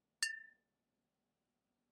glass bottle flick
Bottle Ding Flick Glass Ting sound effect free sound royalty free Sound Effects